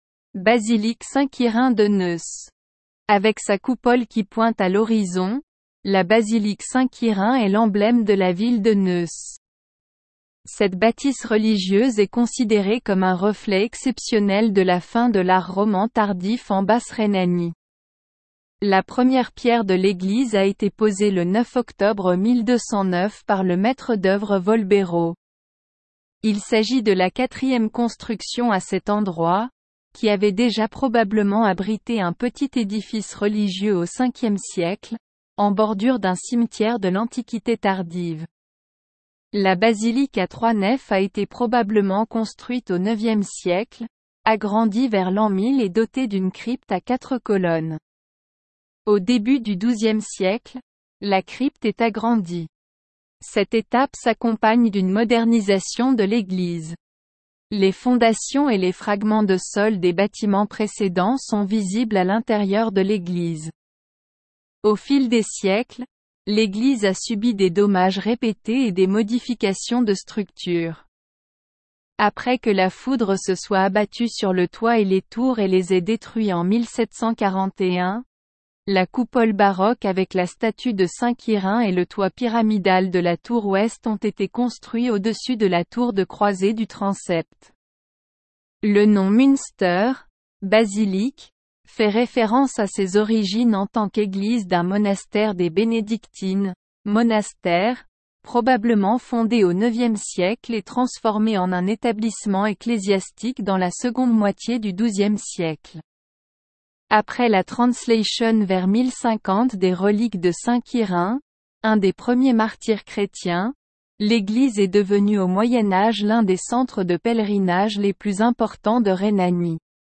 Audio Guide Français